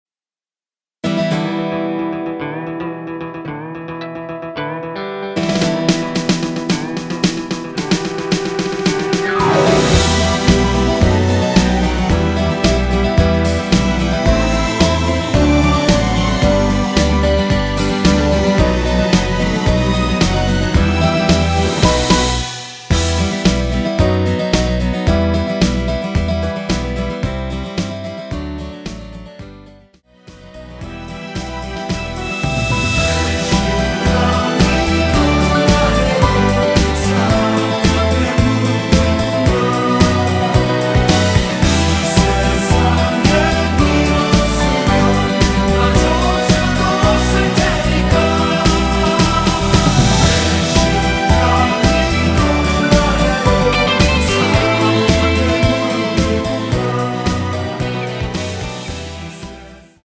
-1)코러스 MR 입니다.
(-1) 내린 코러스 포함된 MR 입니다.(미리듣기 참조)
◈ 곡명 옆 (-1)은 반음 내림, (+1)은 반음 올림 입니다.